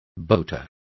Complete with pronunciation of the translation of boasters.